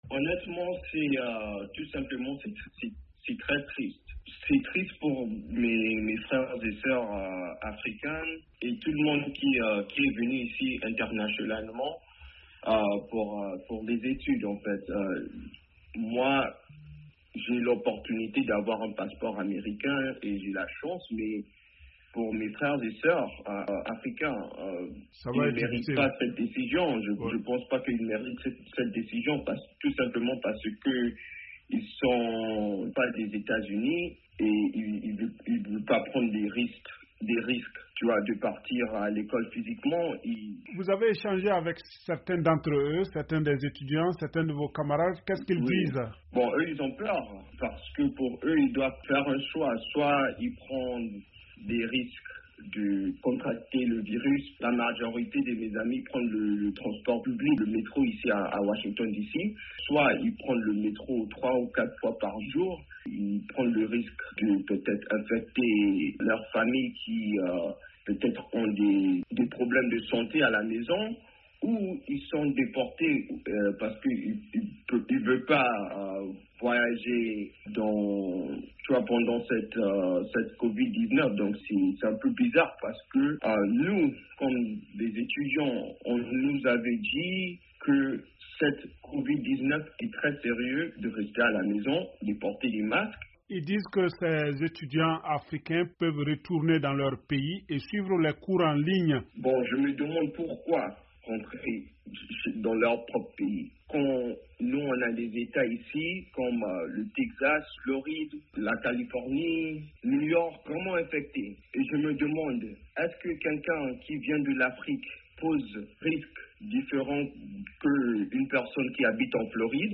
un ancien étudiant originaire du Congo Brazzaville.